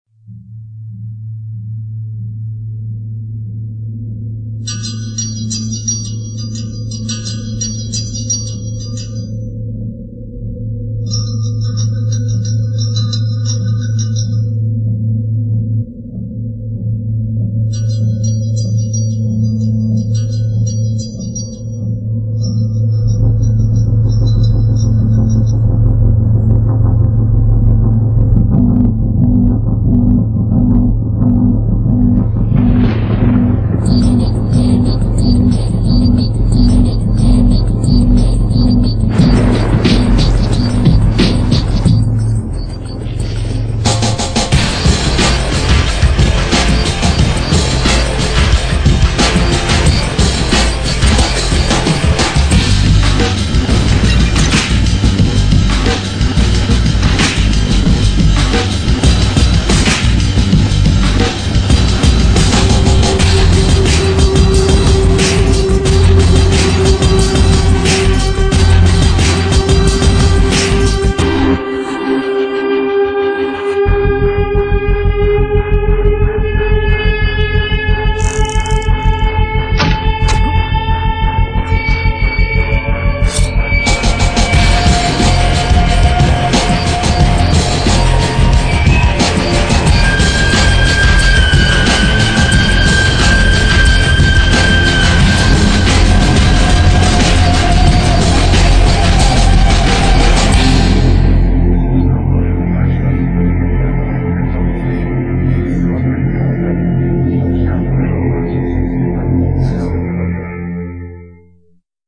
(Recorded from the game)